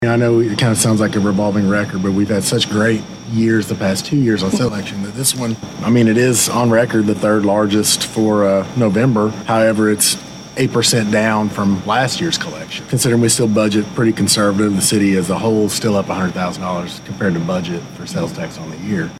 Appearing on KWON Radio's CITY MATTERS program, City Clerk Jason Muninger said,